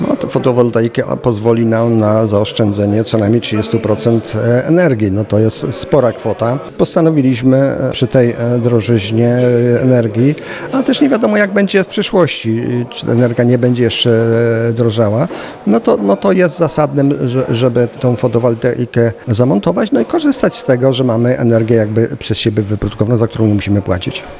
Mówi starosta mielecki Stanisław Lonczak.